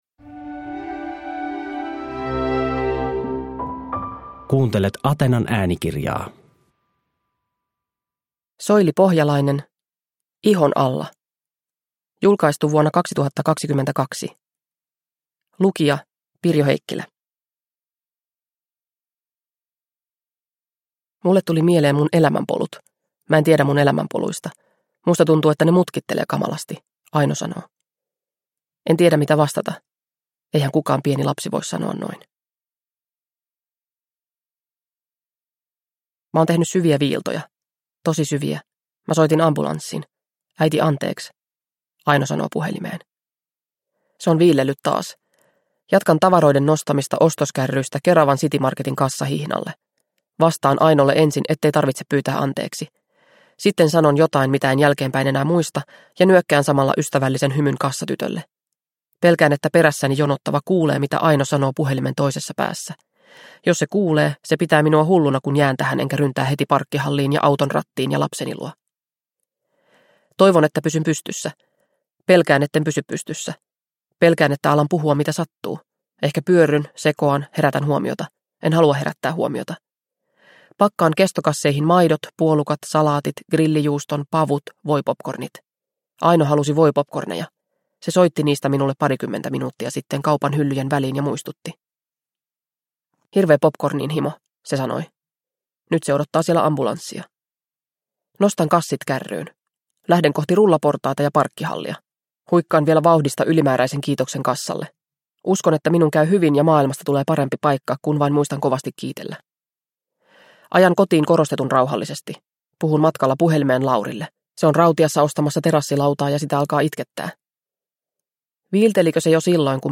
Ihon alla – Ljudbok – Laddas ner